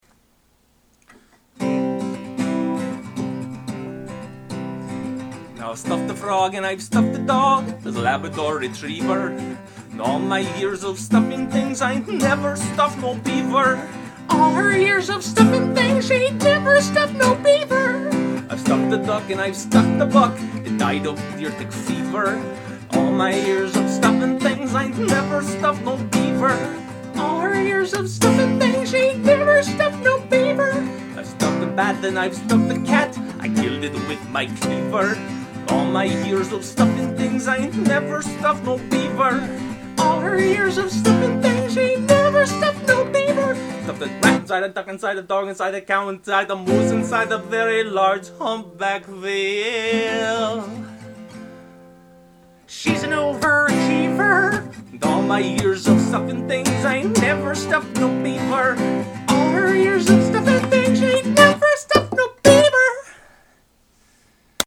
demo track of the above song